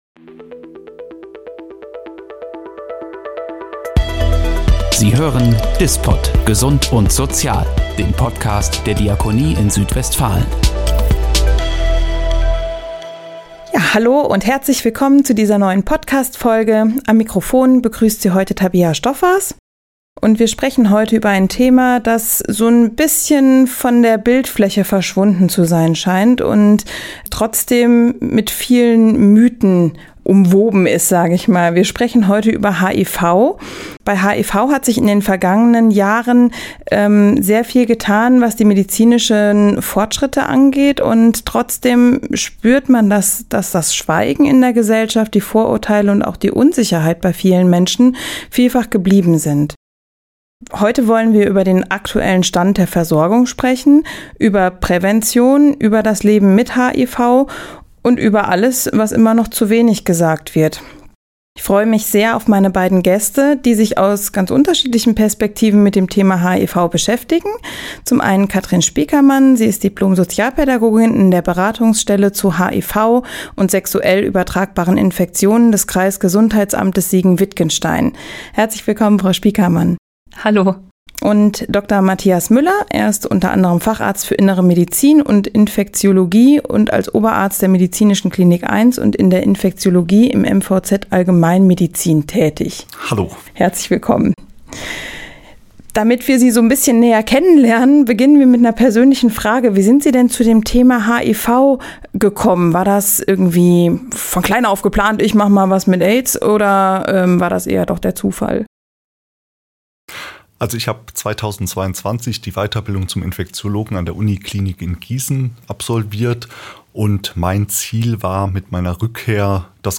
Sie erklären, warum viele Infektionen unentdeckt bleiben, wie ein offener Umgang helfen kann – und was es mit „U = U“ auf sich hat. Ein Gespräch über Wissen, Verantwortung und den Wunsch nach mehr Offenheit im Umgang mit HIV.